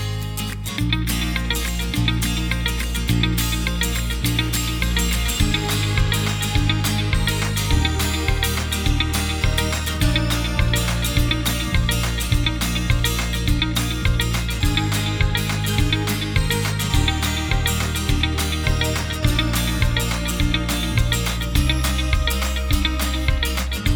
Minus All Guitars Pop (2010s) 3:16 Buy £1.50